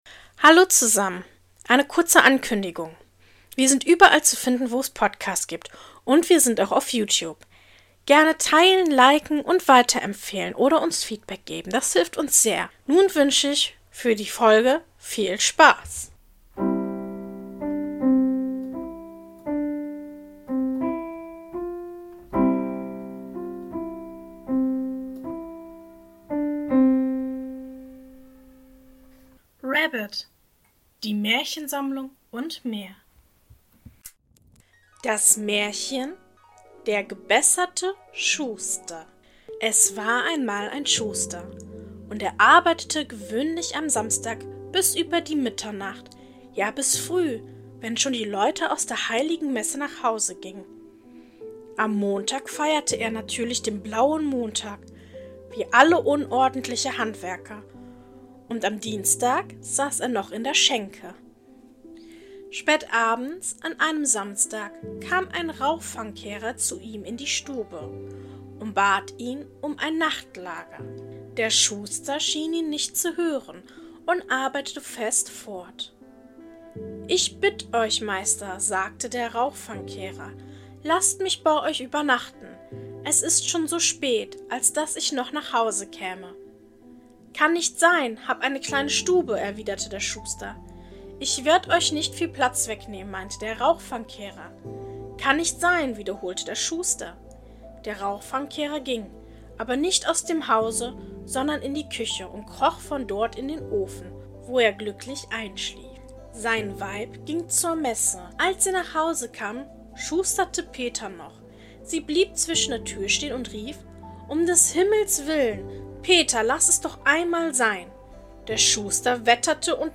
In der heutigen Folge lese ich Folgendes vor: 1. Der gebesserte Schuster. 2. Wie der Wagner König ward.